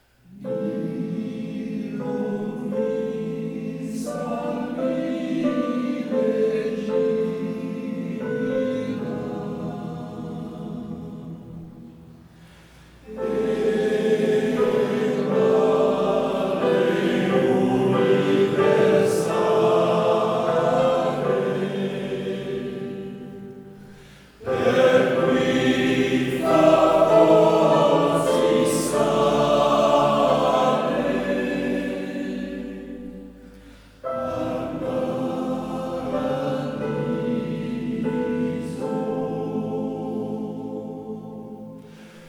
Chants liturgiques